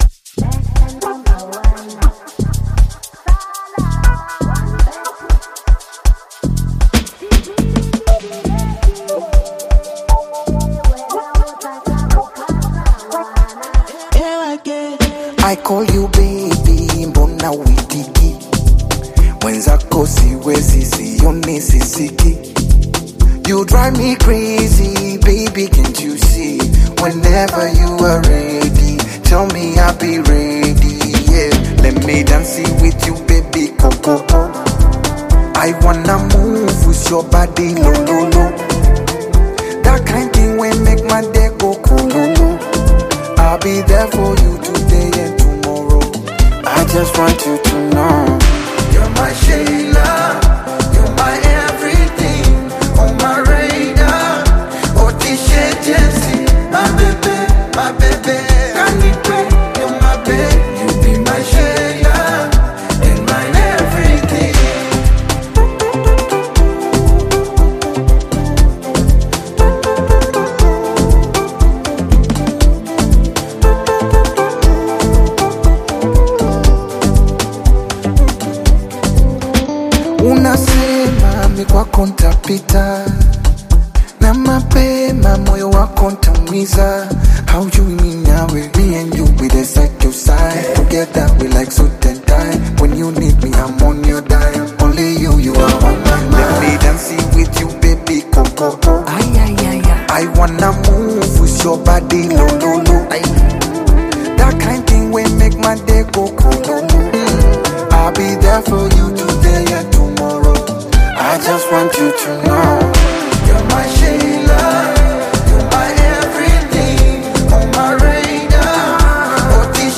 R&B with African beats